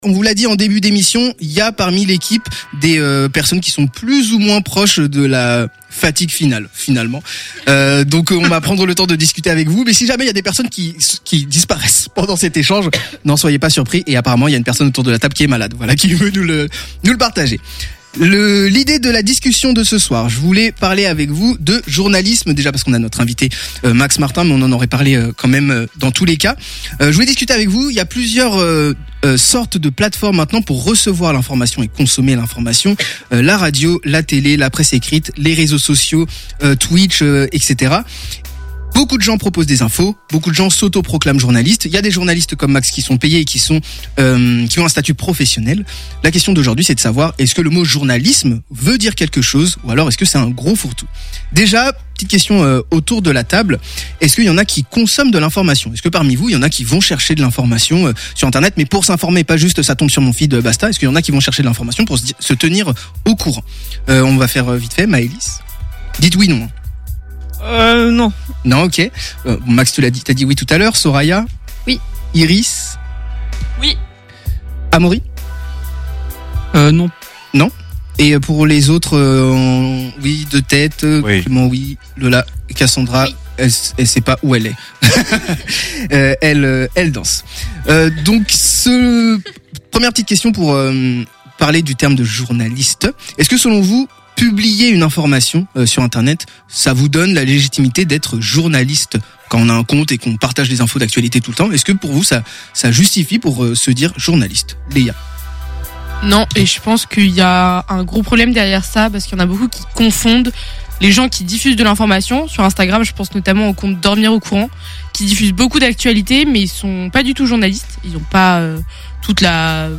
Débat - G!